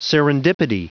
Prononciation du mot : serendipity